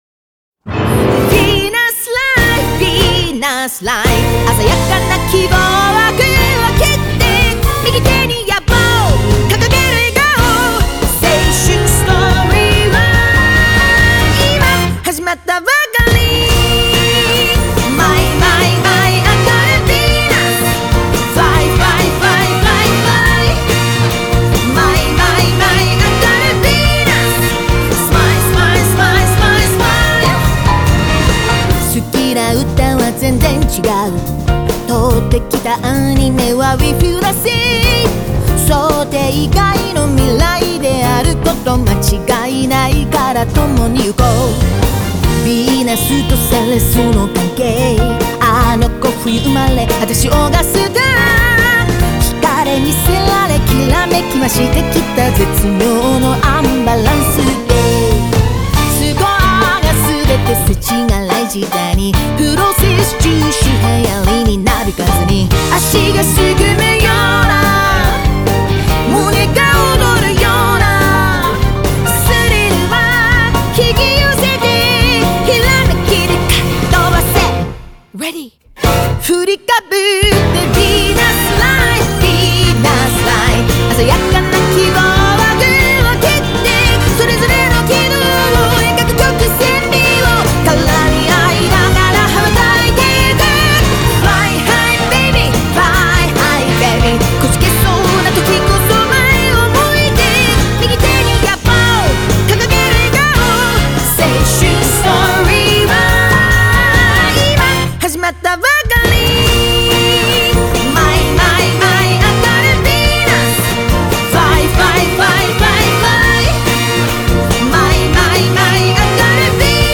Genre : Anime.